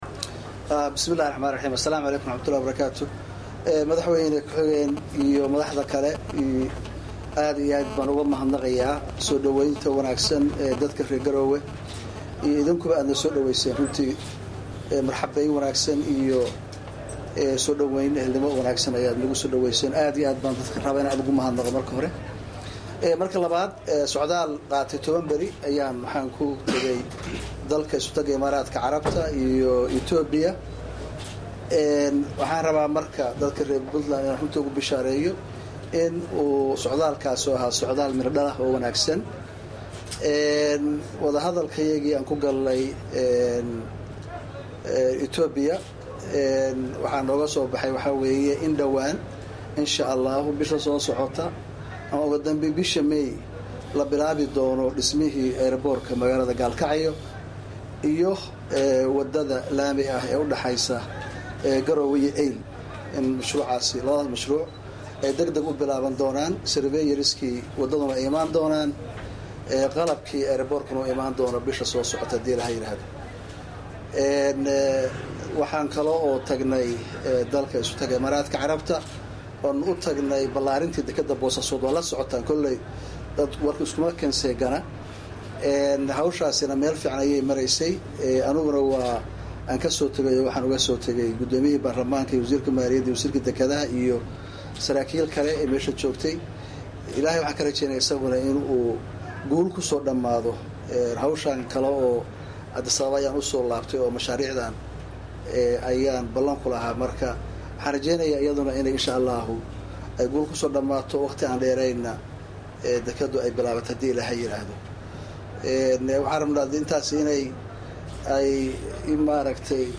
codka-madaxweynaha1.mp3